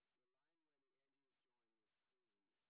sp22_white_snr20.wav